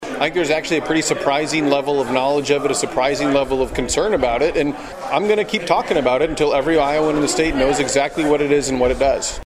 Sand, who is the only Democrat holding a statewide office, spoke to the Sioux City Rotary Club and later held a town hall in Onawa. He says the bill has been a hot topic.